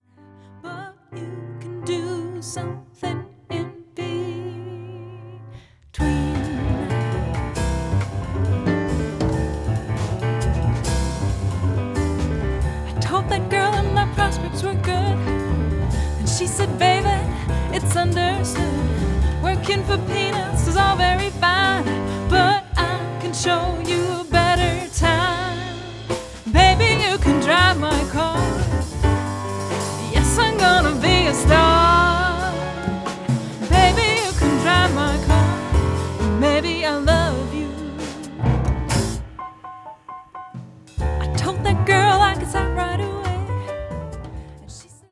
voc
piano
bass
drums